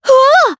Leo-Vox_Damage_jp_03.wav